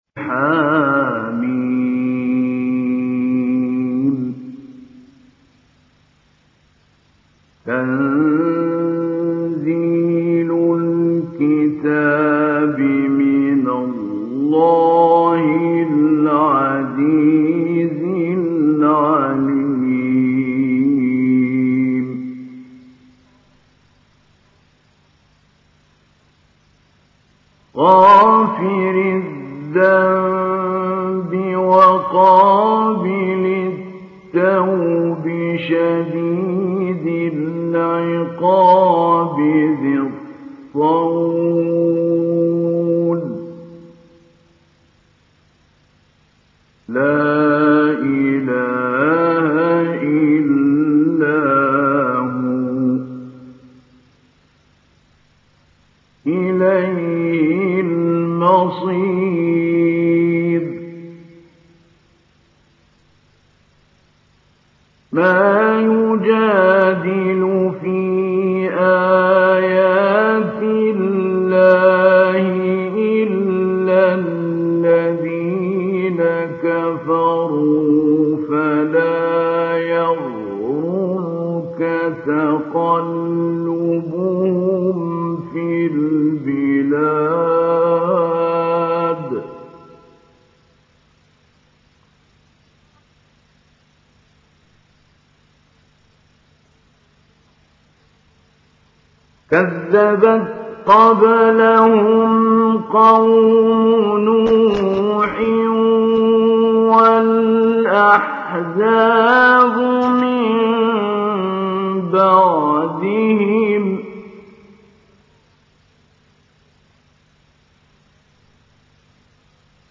Download Surat Ghafir Mahmoud Ali Albanna Mujawwad